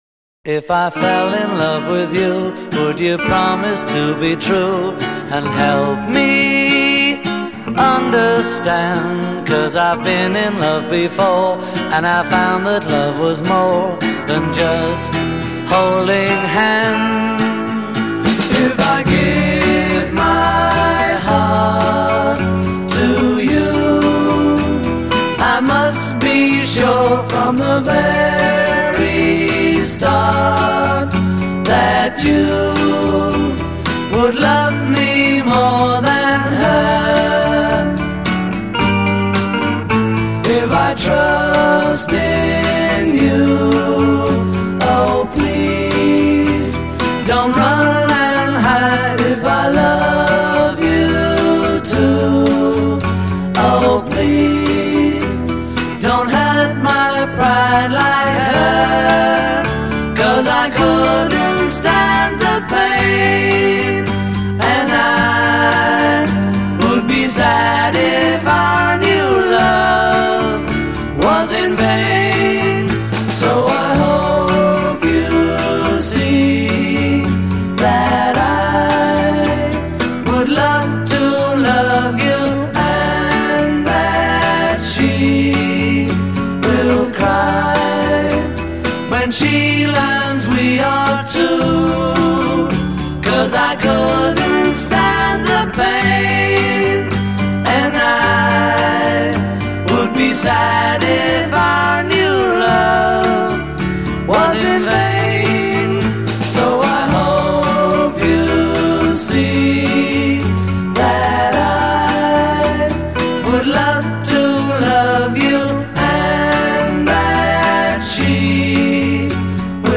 It was a nice harmony number, very much a ballad.
i intro 0:00 8 solo voice; acoustic and electric guitar a
A verse 0: 8 double track vocals; harmony > unison b